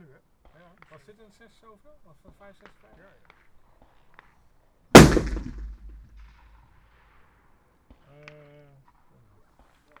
Environmental
Streetsounds
Noisepollution
UrbanSoundsNew / 01_gunshot /shot556_29_ch01_180718_162104_16_.wav